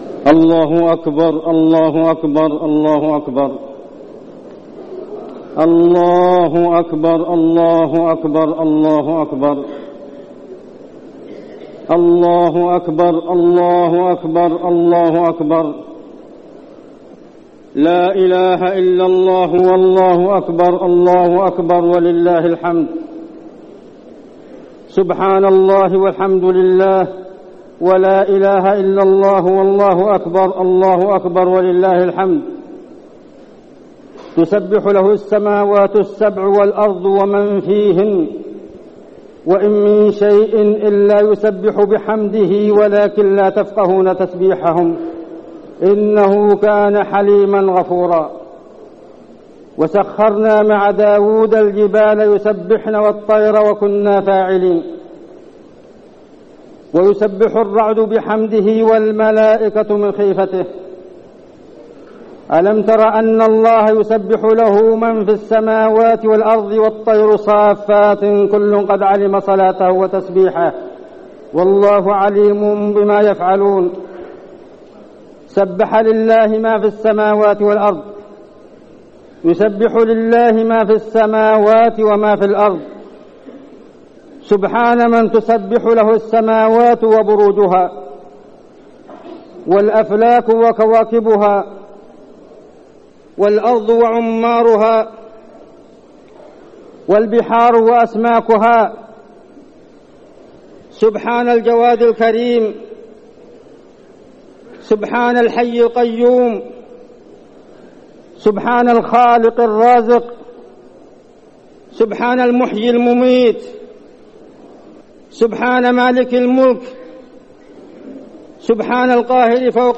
خطبة عيد الفطر - المدينة - الشيخ عبدالله الزاحم
تاريخ النشر ١ شوال ١٤١٣ هـ المكان: المسجد النبوي الشيخ: عبدالله بن محمد الزاحم عبدالله بن محمد الزاحم خطبة عيد الفطر - المدينة - الشيخ عبدالله الزاحم